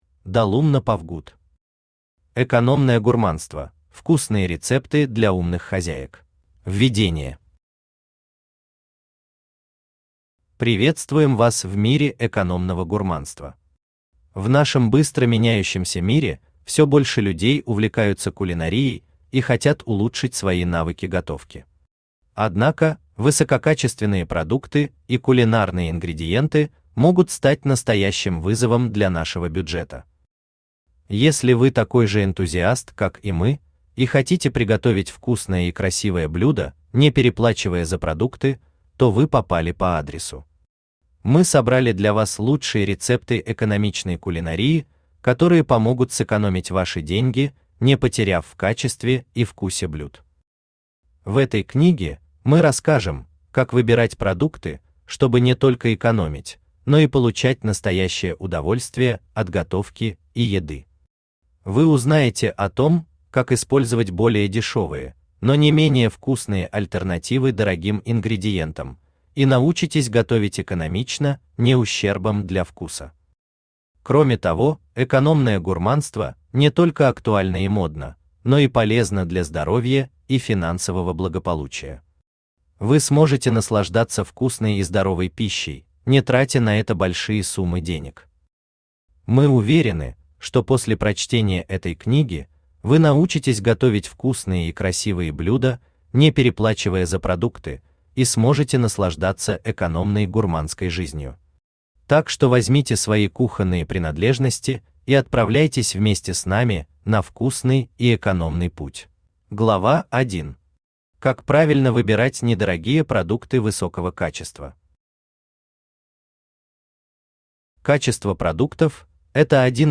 Аудиокнига Экономное гурманство: вкусные рецепты для умных хозяек | Библиотека аудиокниг